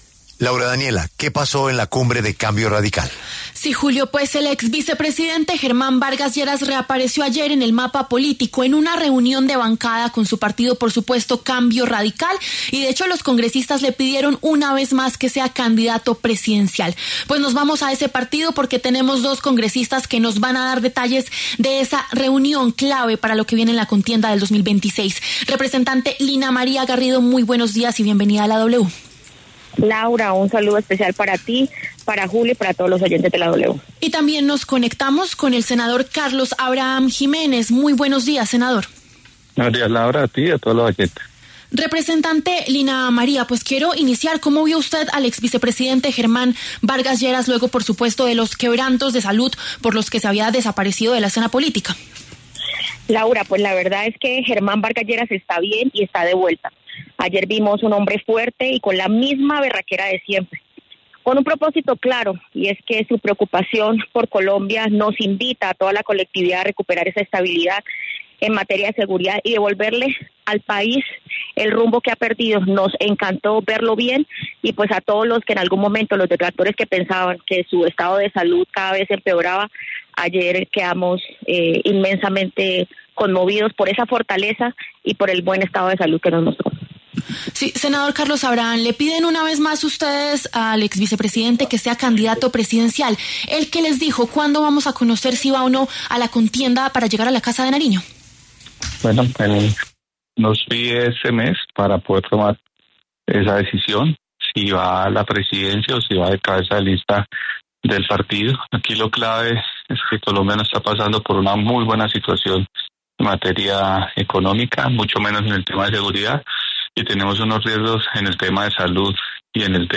La representante Lina María Garrido y el senador Carlos Abraham Jiménez pasaron por los micrófonos de La W.